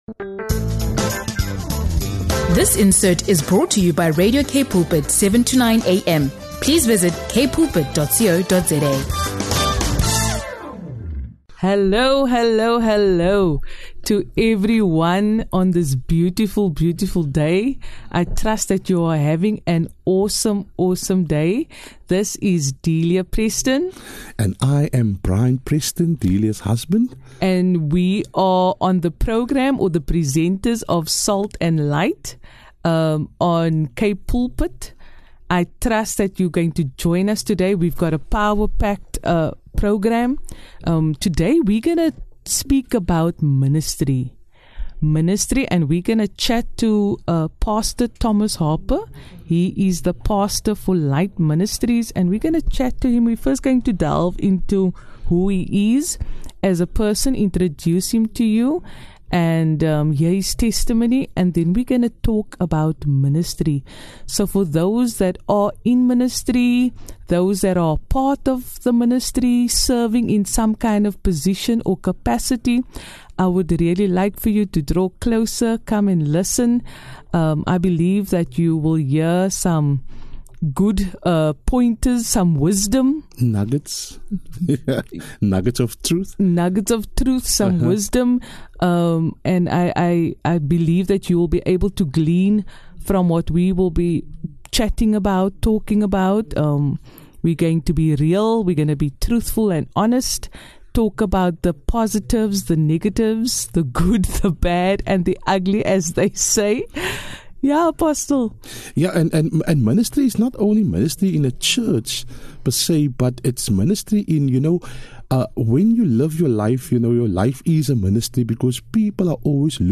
a conversation for anyone serving in or outside the church.